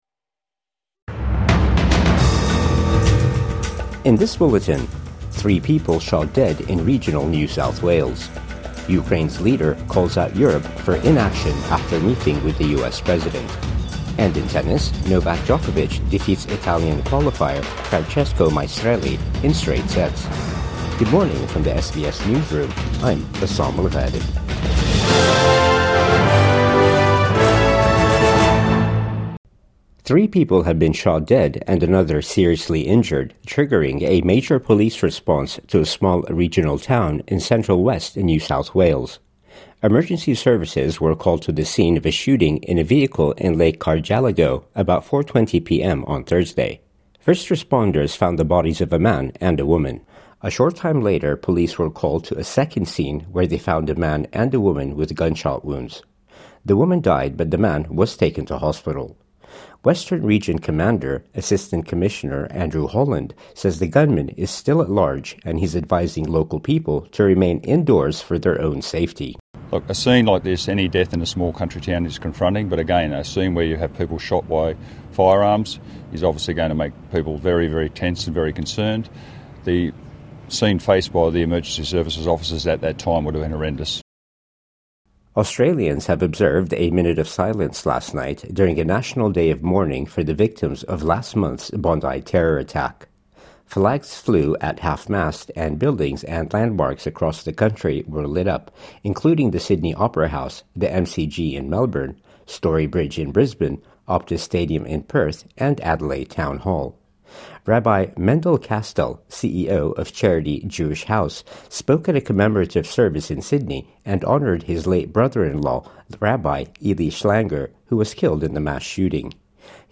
Three shot dead and suspects still not apprehended | Morning News Bulletin 23 January 2026